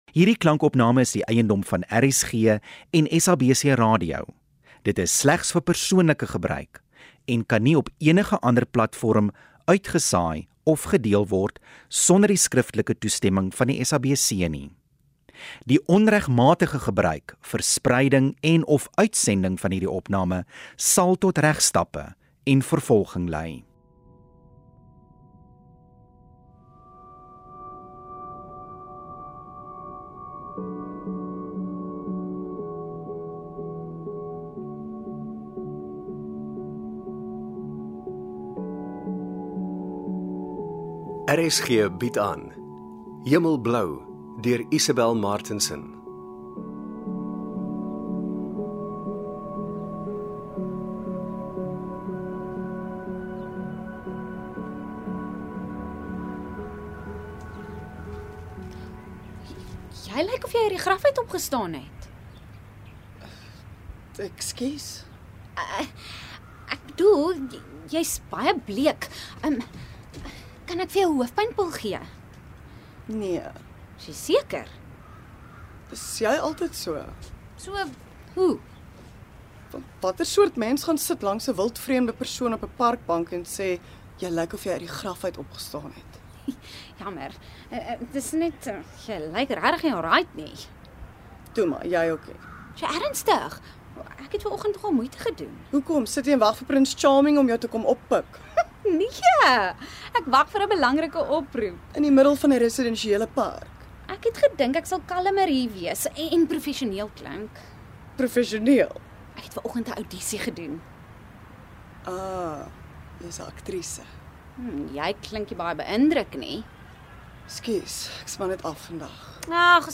Die tweede radioteaterseisoen is volstoom aan die gang!